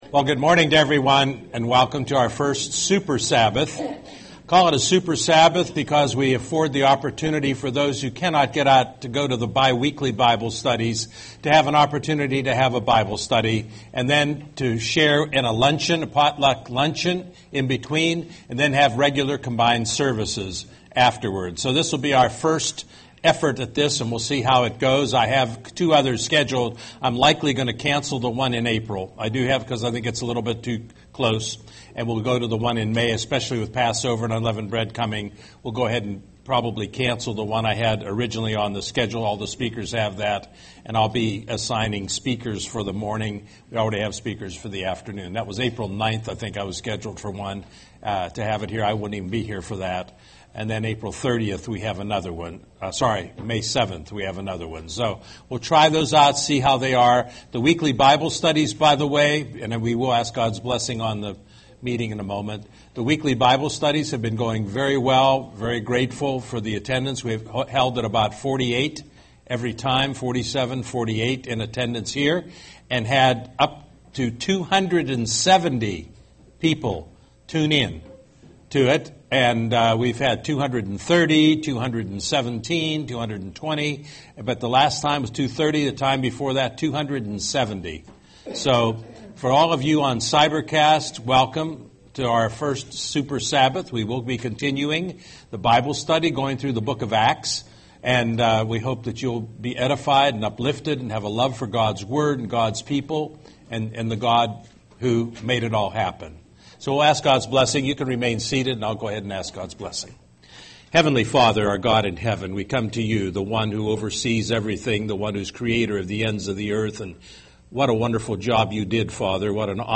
Part 4 of the Acts Bible study series